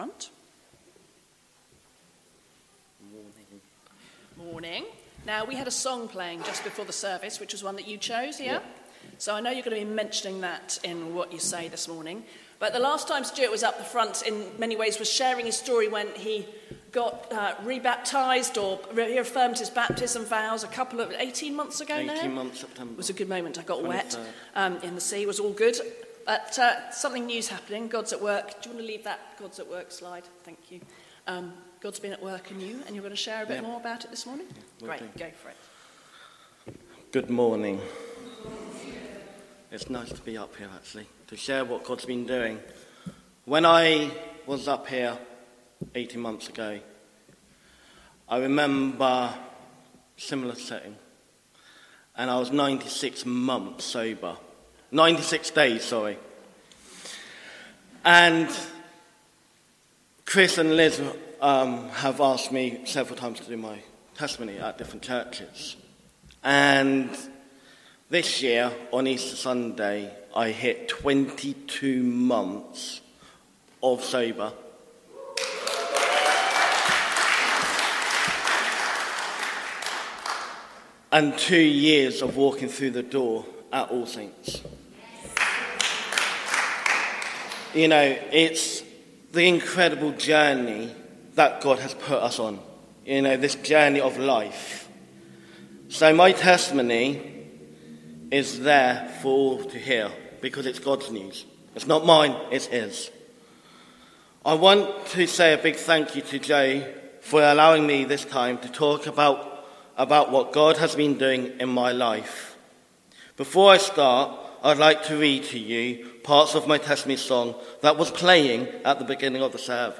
The song, Sovereign Over Us, was sung.